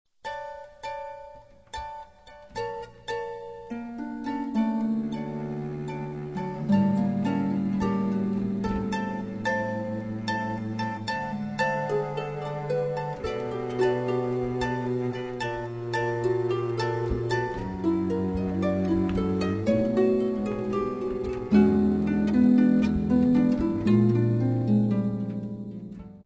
harp
'cello
voice & percussion
flute
violin.
(all with cello except where indicated)